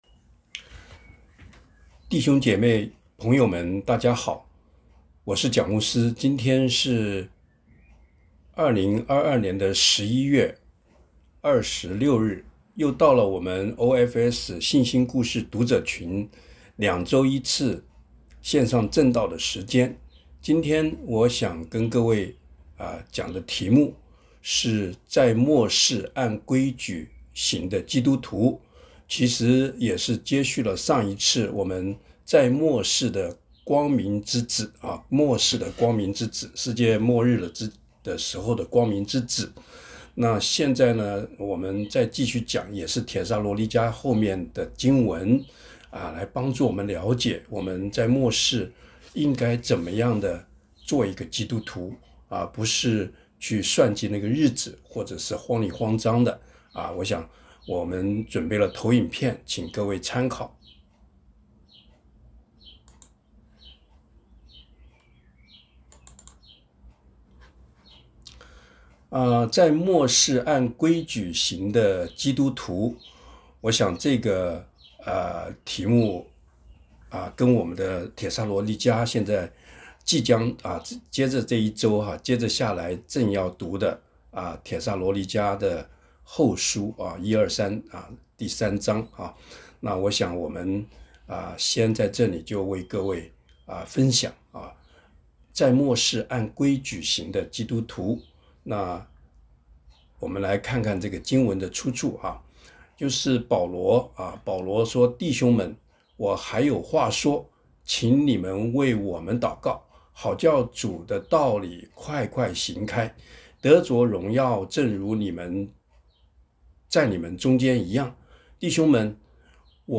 今天是每两周1次的主日时间。